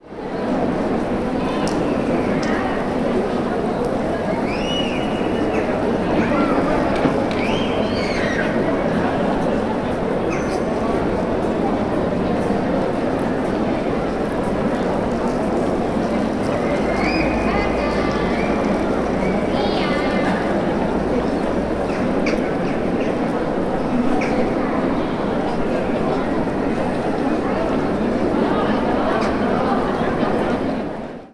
Ambiente tranquilo de zona peatonal
barullo
bullicio
gorjeo
grito
murmullo
Sonidos: Gente
Sonidos: Ciudad